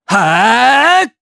Neraxis-Vox_Casting3_jp.wav